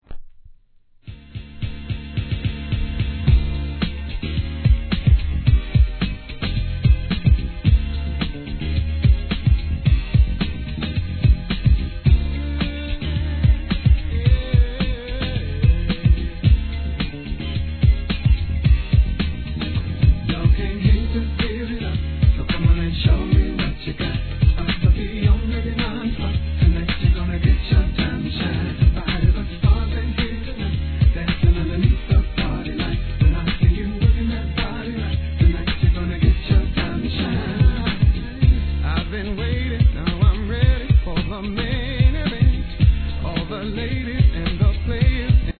HIP HOP/R&B
今回の新作は、軽快UP TEMPOでのダンサブルナンバー♪